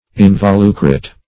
involucret - definition of involucret - synonyms, pronunciation, spelling from Free Dictionary Search Result for " involucret" : The Collaborative International Dictionary of English v.0.48: Involucret \In`vo*lu"cret\, n. (Bot.)